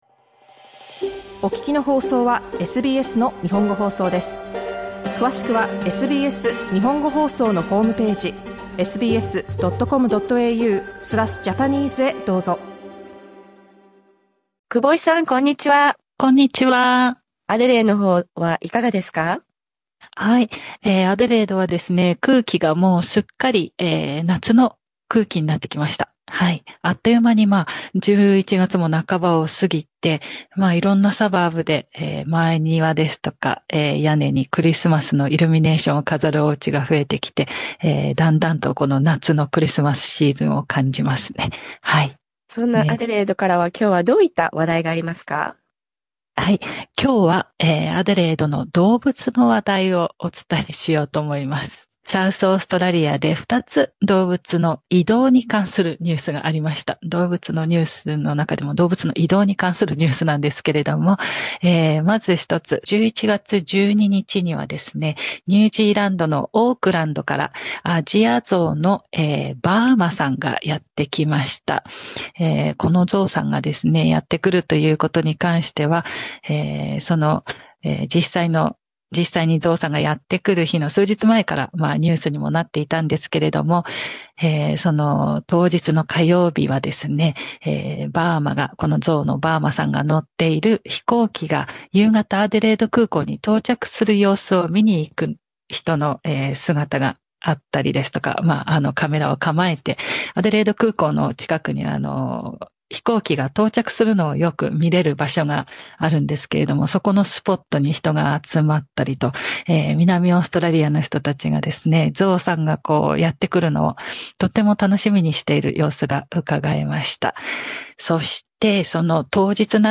Our Tuesday segment, Australia Wide covers local events and useful community information from six cities across Australia .